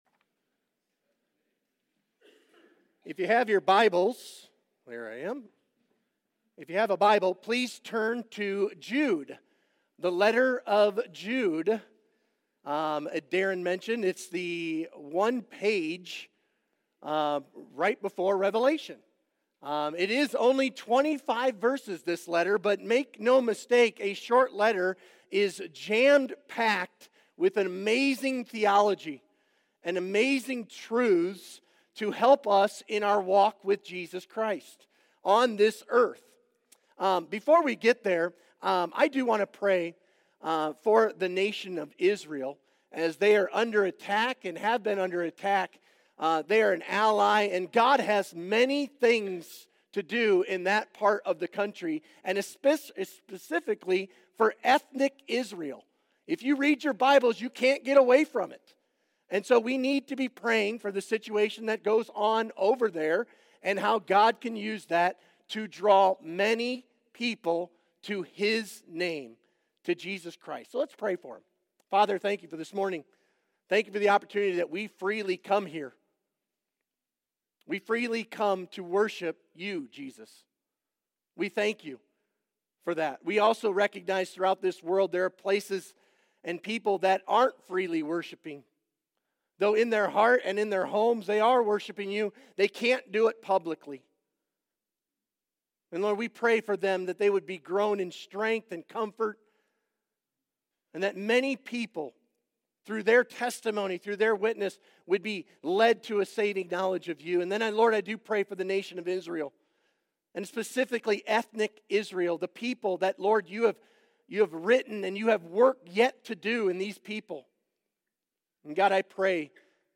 Sermon Questions Read Jude 1-25.